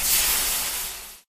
fuse.ogg